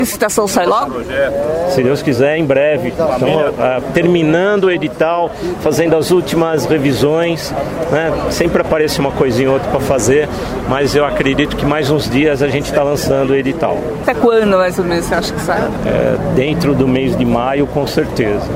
O secretário de mobilidade de Santo André, Edilson Factori, disse durante a apresentação de um novo aplicativo de celular para os passageiros, que o edital deve ser lançado ainda neste mês.